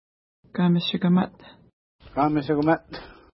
Pronunciation: ka:miʃikəma:t
Pronunciation